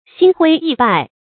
心灰意败 xīn huī yì bài
心灰意败发音
成语注音 ㄒㄧㄣ ㄏㄨㄟ ㄧˋ ㄅㄞˋ